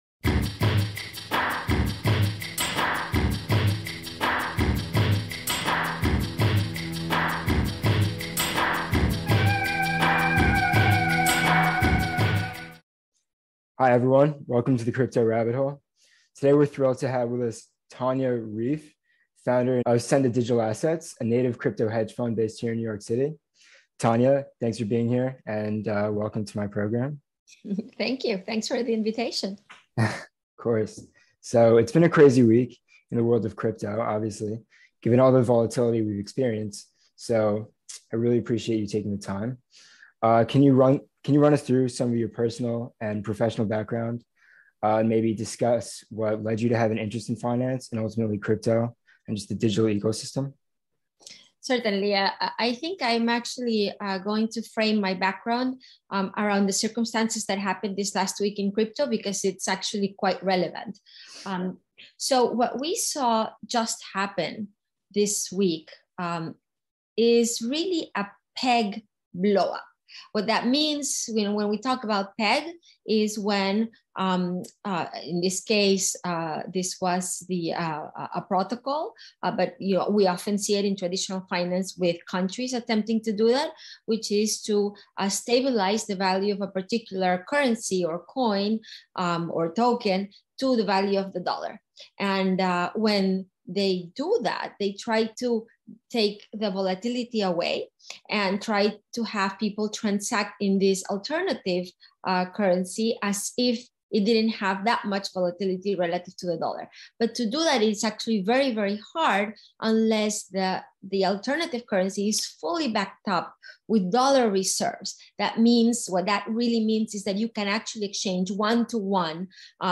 Investing in Crypto During Volatile Times - A Conversation